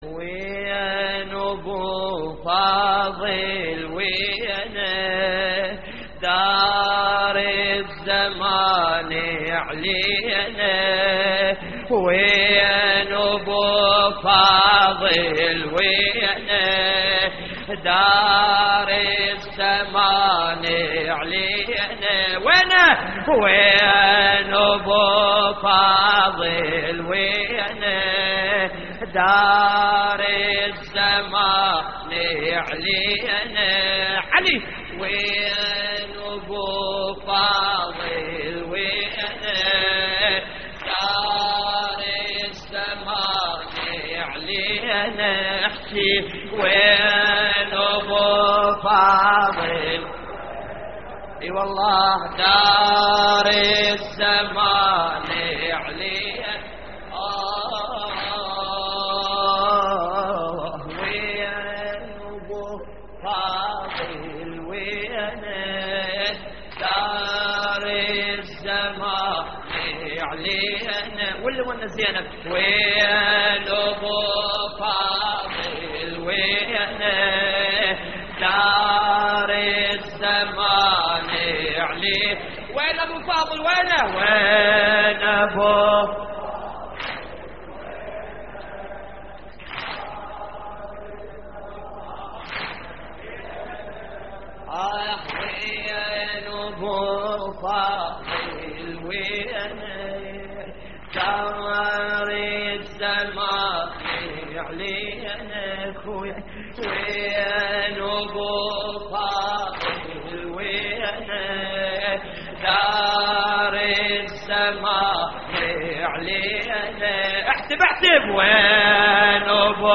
اللطميات الحسينية